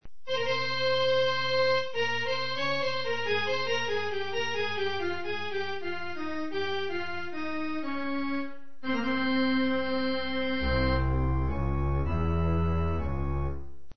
orgue.mp3